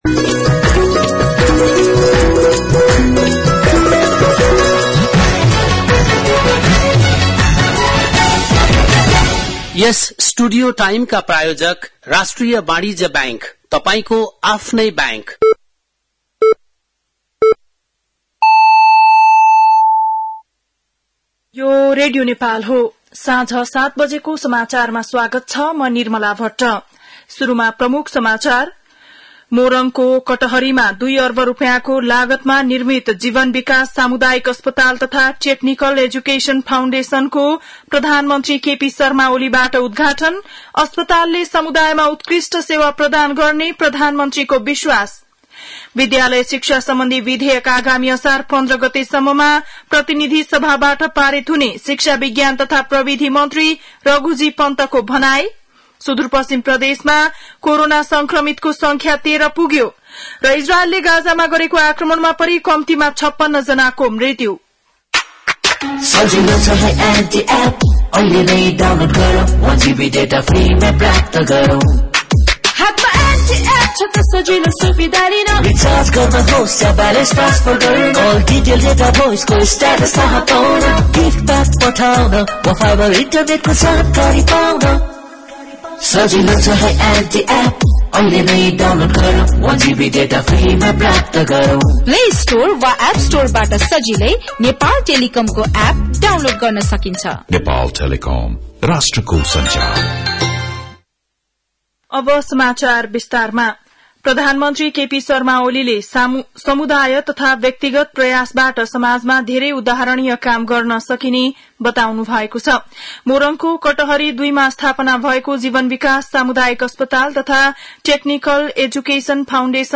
बेलुकी ७ बजेको नेपाली समाचार : २४ जेठ , २०८२
7.-pm-nepali-news-.mp3